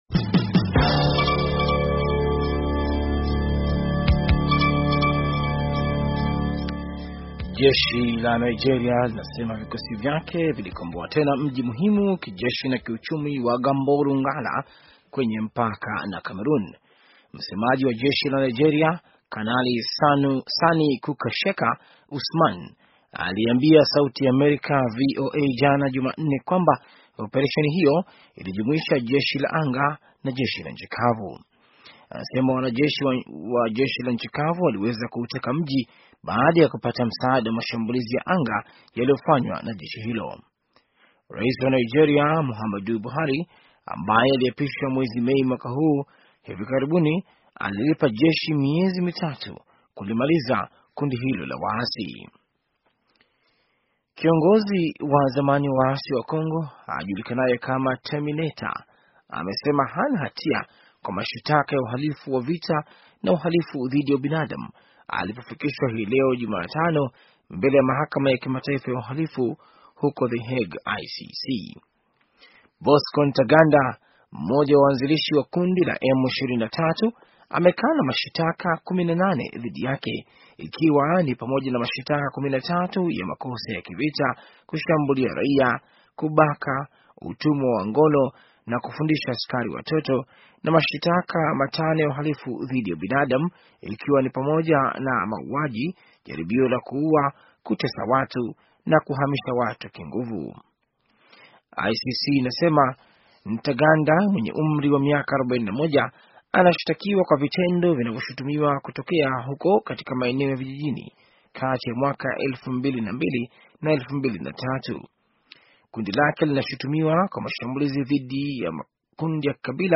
Taarifa ya habari - 5:14